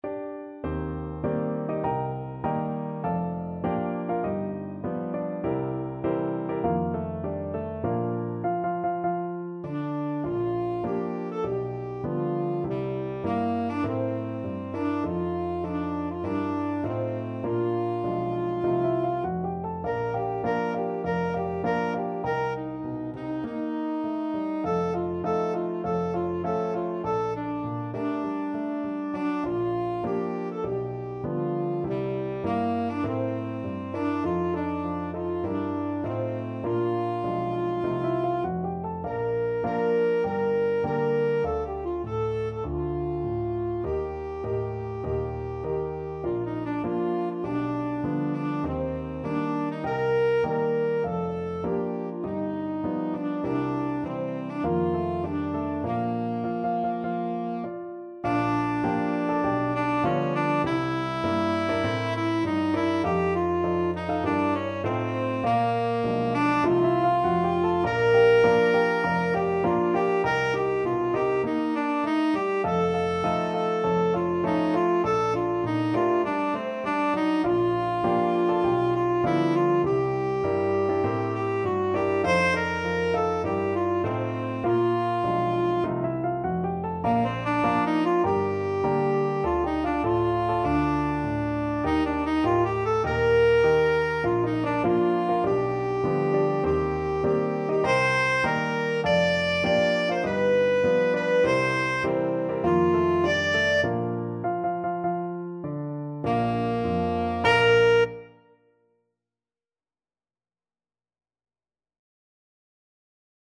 4/4 (View more 4/4 Music)
Moderato = c. 100
Jazz (View more Jazz Tenor Saxophone Music)